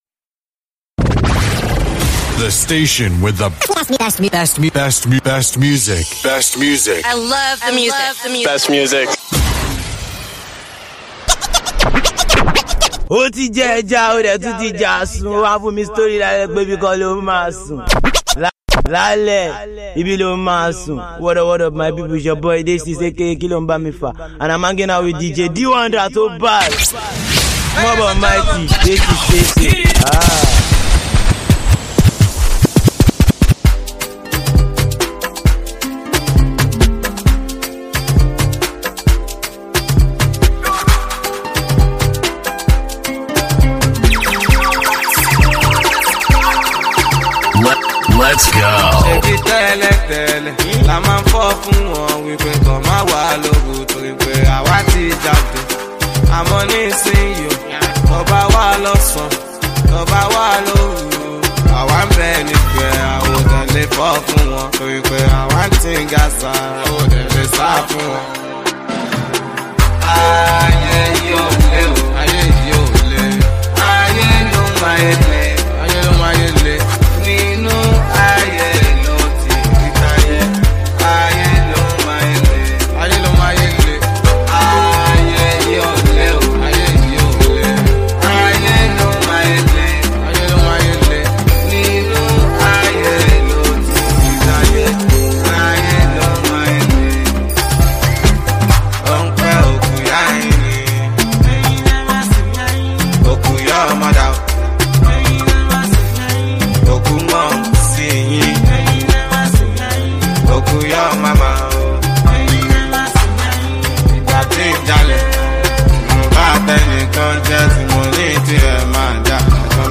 party mixtape